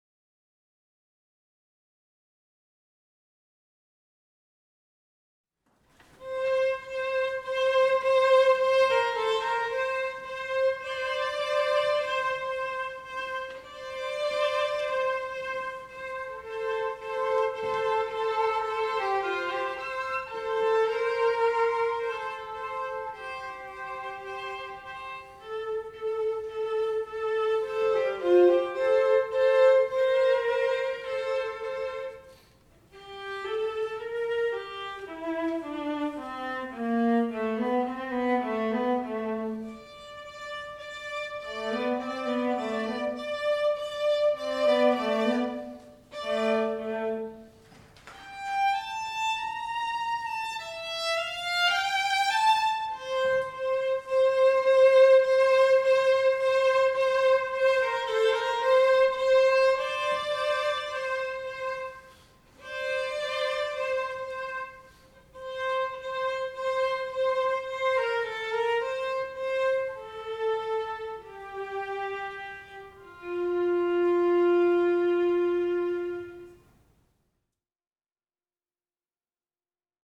Die folgenden Aufnahmen sind Konzertmitschnitte des Jahreskonzertes 2007, es spielt Sinfonietta Dresden